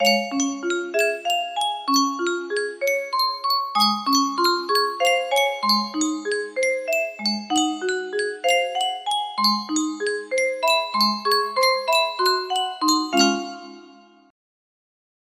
Yunsheng Music Box - Over the Waves Y094 music box melody
Full range 60